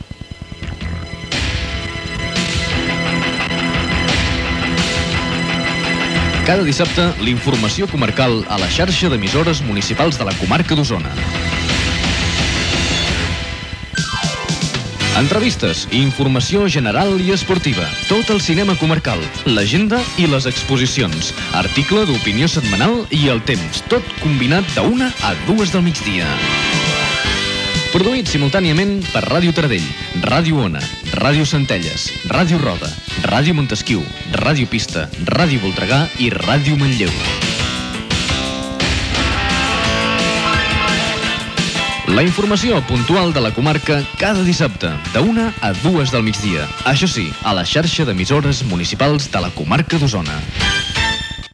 Careta de l'informatiu de Ràdio Taradell
Informatiu